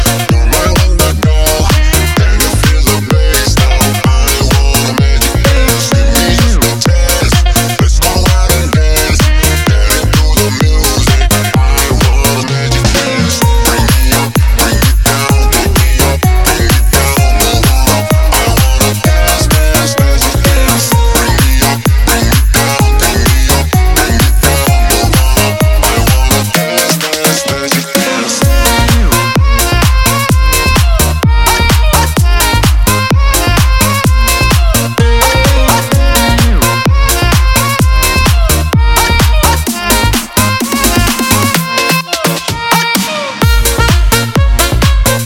позитивные
заводные
house
легкие